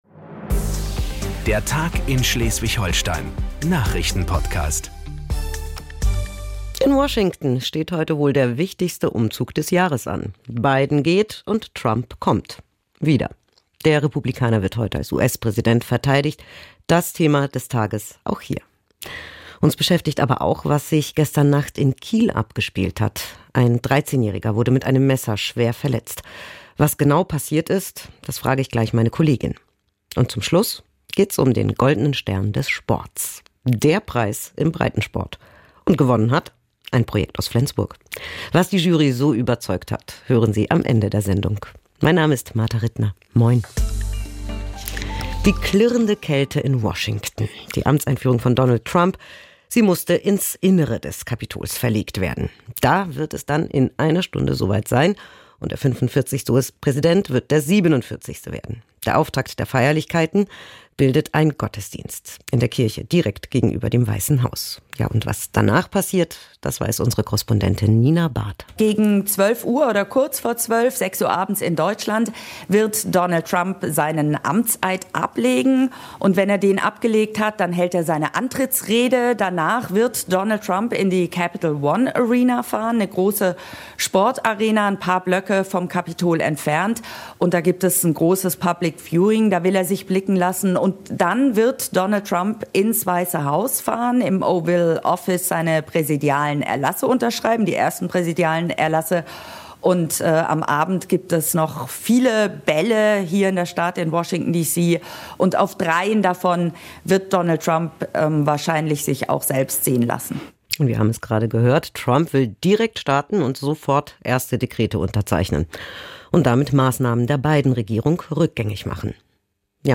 1 Der Tag in SH vom 20.01.2025 8:17 Play Pause 5h ago 8:17 Play Pause Spela senare Spela senare Listor Gilla Gillad 8:17 Der Tag in Schleswig-Holstein - alles was wichtig ist für Schleswig-Holstein hören Sie im Nachrichtenpodcast von NDR Schleswig-Holstein. Wir fassen den Tag zusammen, ordnen ein und beleuchten Hintergründe.